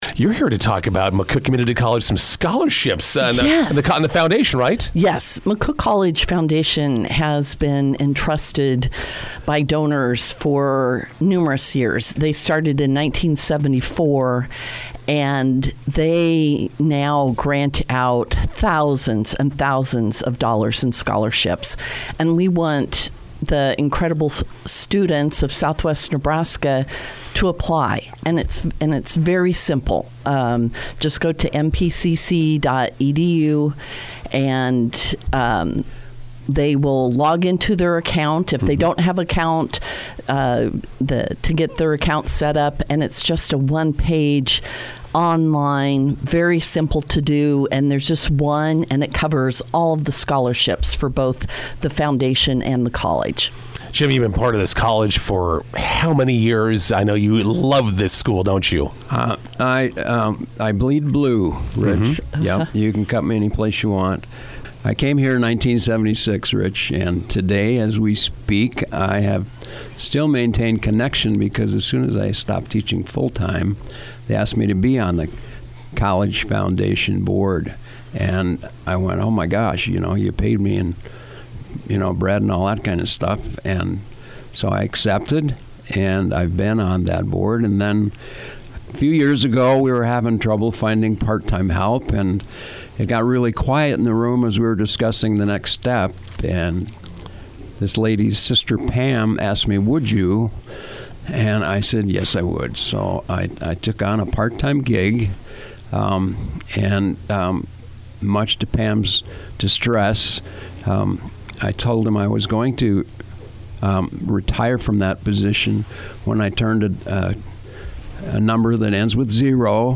INTERVIEW: McCook Community College Foundation Scholarship deadline approaching.